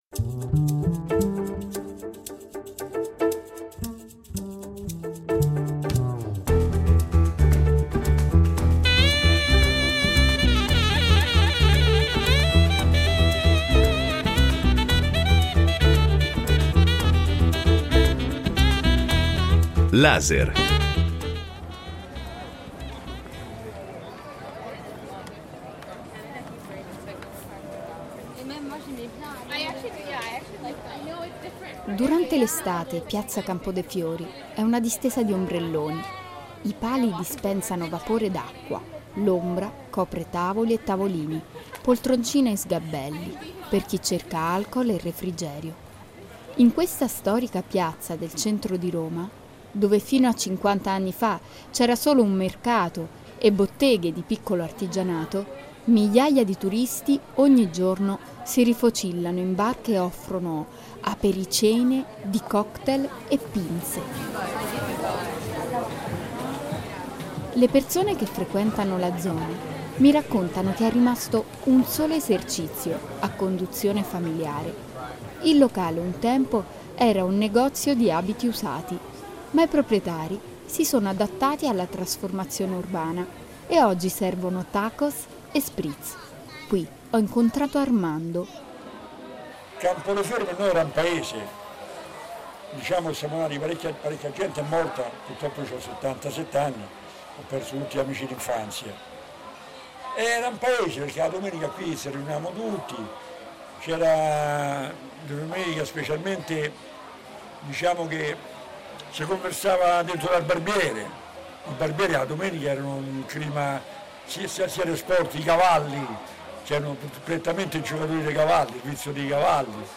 Quali sono i motivi di questa repentina mutazione demografica e dove sono andate ad abitare le famiglie che prima risiedevano nel primo municipio? Dopo Roma è il cadavere di una nonna - l’audio documentario che indagava sul centro della capitale nell’era del turismo - Vado a vivere in collina prosegue nella raccolta di testimonianze che raccontano lo svuotamento del centro storico e lo slittamento della classe popolare verso la periferia.
Abbiamo passeggiato per i lunghi corridoi e parlato con abitanti, architetti, sociologi, operatori sociali.